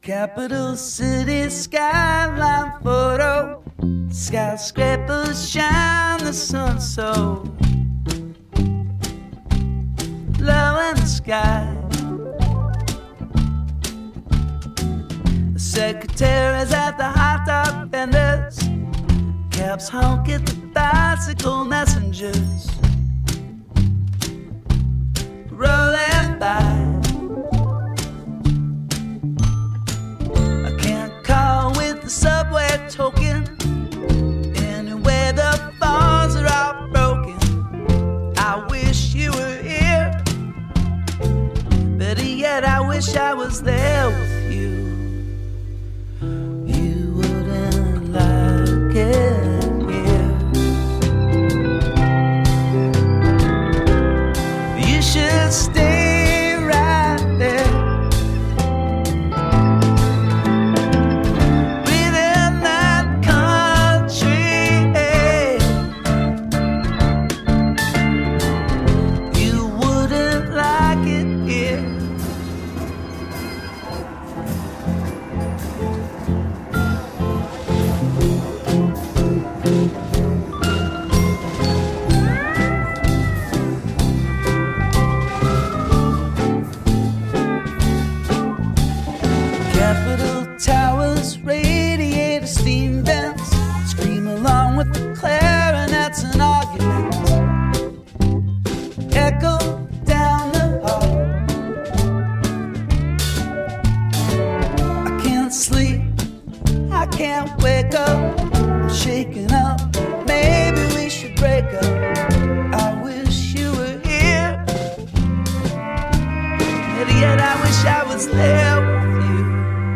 styled jaunt